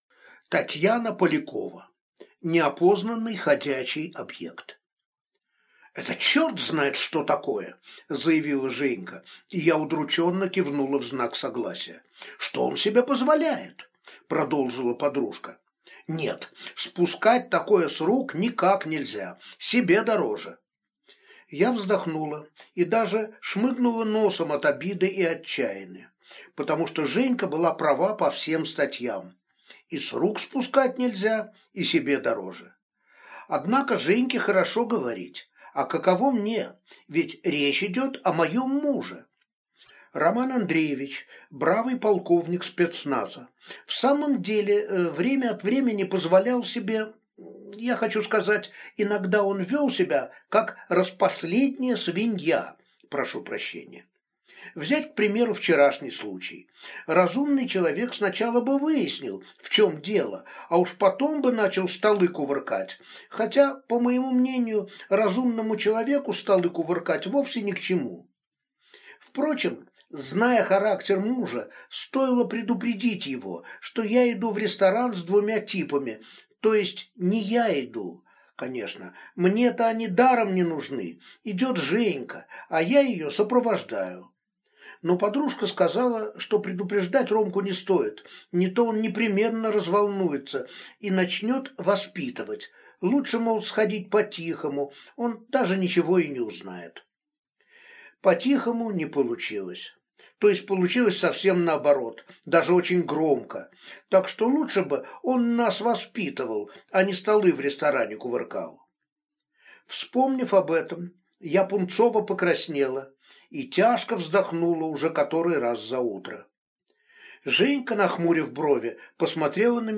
Аудиокнига Неопознанный ходячий объект | Библиотека аудиокниг